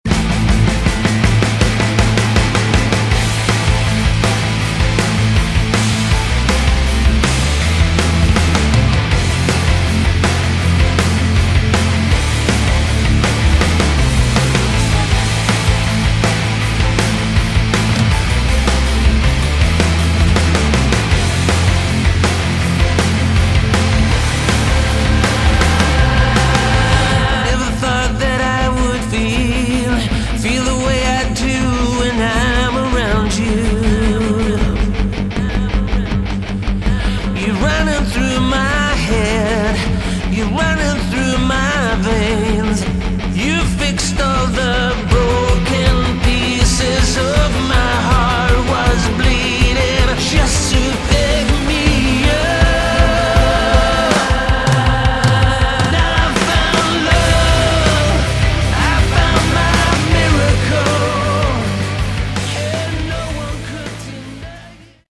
Category: Melodic Rock
vocals, guitars, keyboards
lead guitars
bass, backing vocals
drums, percussion
saxophone